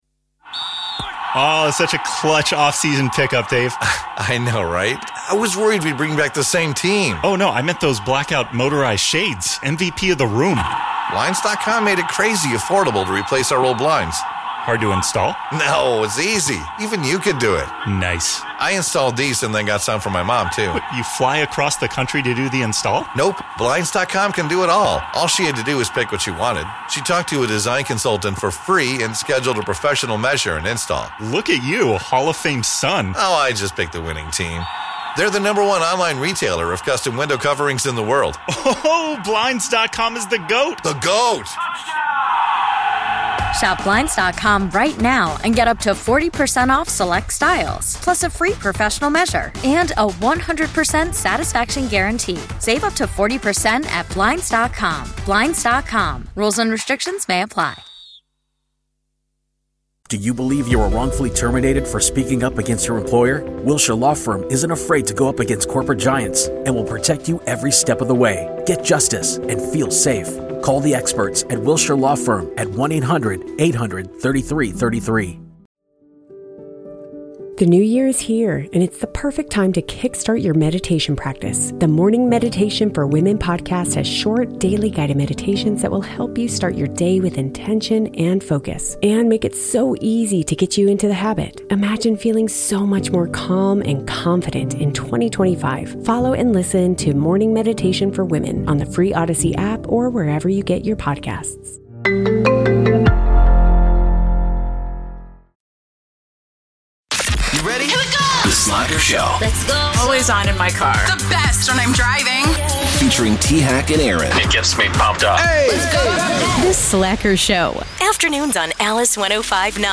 Indulge your inner curiosity with caller driven conversation that makes you feel like you’re part of the conversation—or even better, eavesdropping on someone else’s drama.